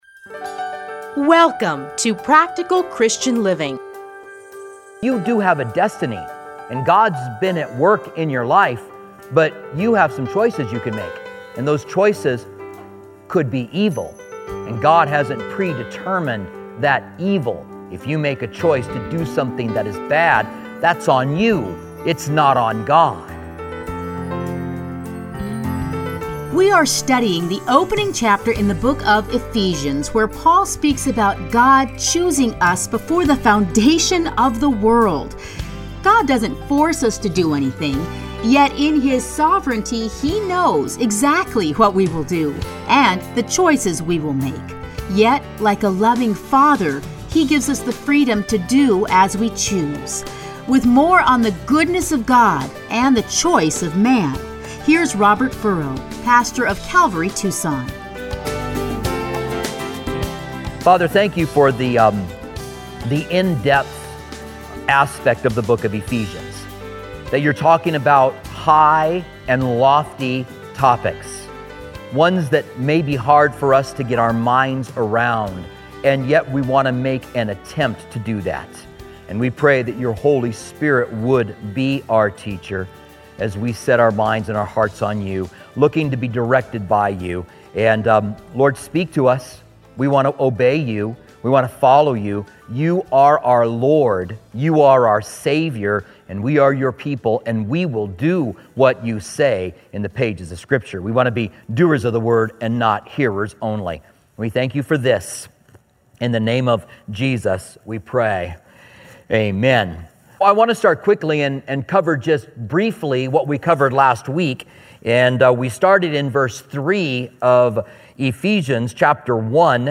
Listen here to his commentary Ephesians.